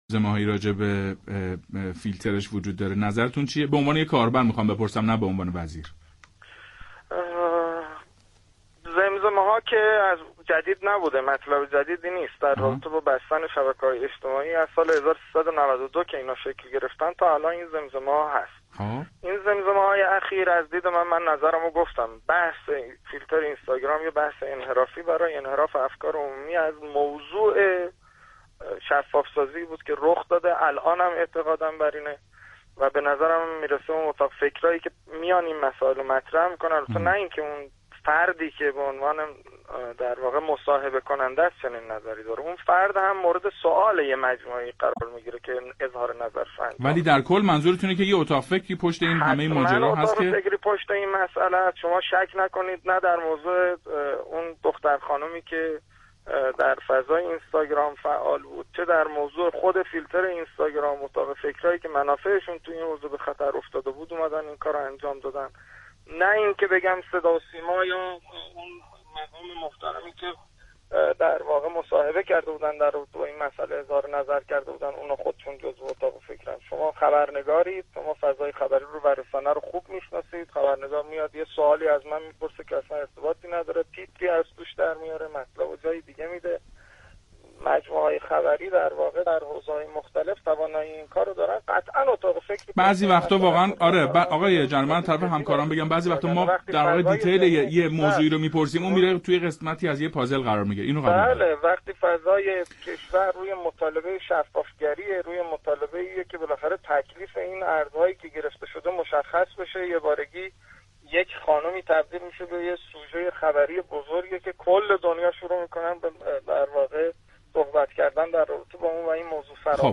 به گزارش خبرنگار علمی باشگاه خبرنگاران پویا؛ محمد جواد آذری جهرمی در گفت‌وگویی تلفنی با یکی از برنامه‌های رادیویی امروز درباره بحث فیلترینگ اینستاگرام که زمزمه‌های آن مطرح شده است، اظهار کرد: همانطور که قبلا گفتم، این زمزمه‌ها مطلب جدیدی نیست و موضوع بستن شبکه‌های اجتماعی از سال 92 شکل گرفت.